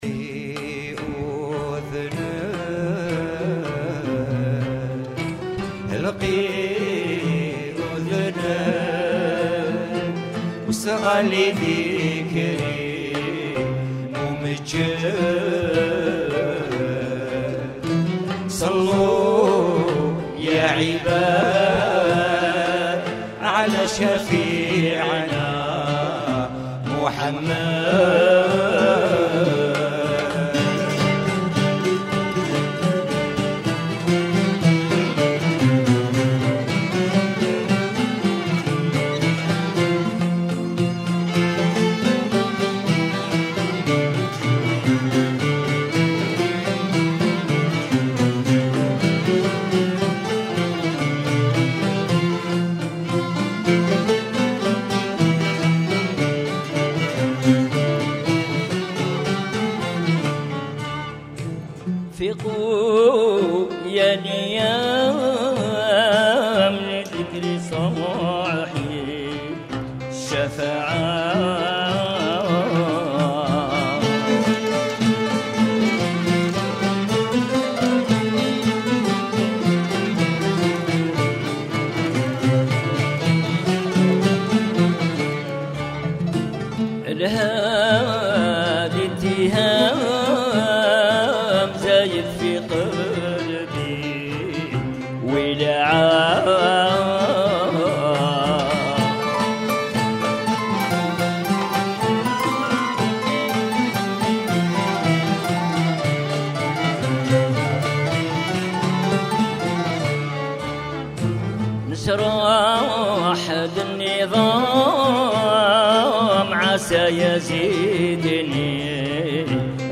-11- طرب غرناطي